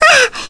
May-Vox_Damage_kr_01.wav